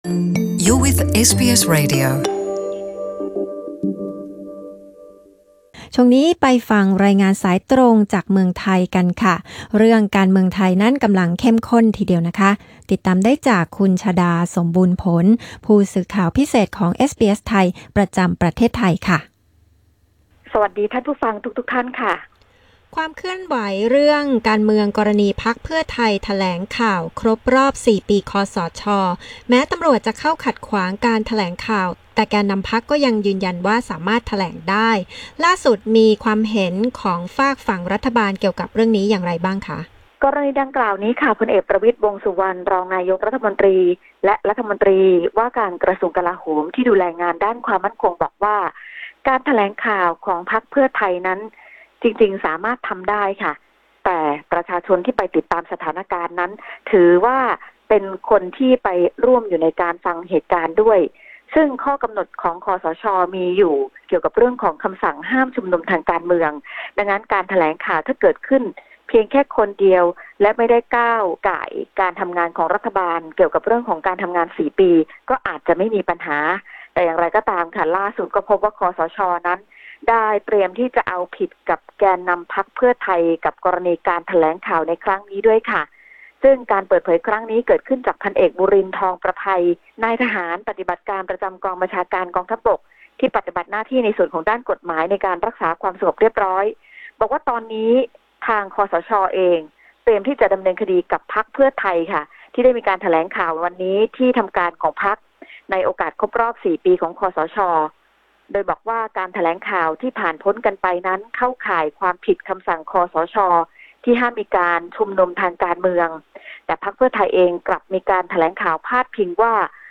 รายงานข่าวสายตรงจากเมืองไทย 17 พ.ค. 2018